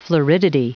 Prononciation du mot floridity en anglais (fichier audio)
Prononciation du mot : floridity